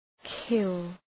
Προφορά
{kıl}